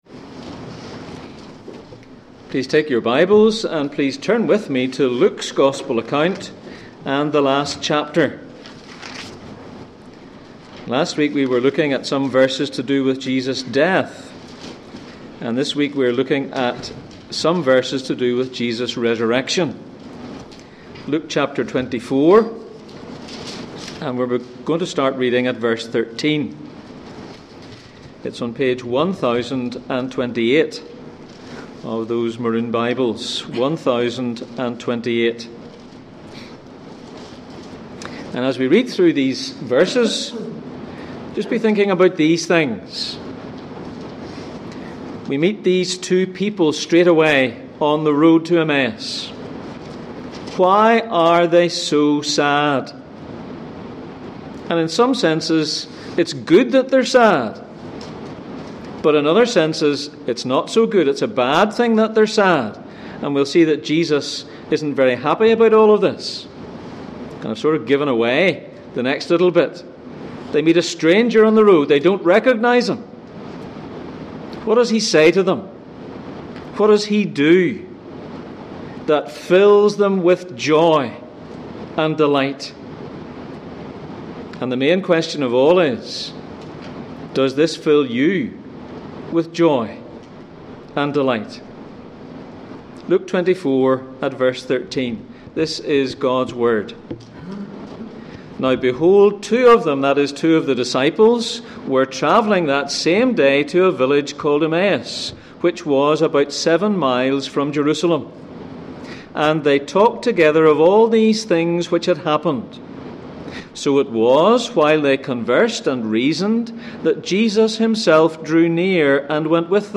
Passage: Luke 24:13-35, Genesis 3:15, Genesis 22:9-14 Service Type: Sunday Morning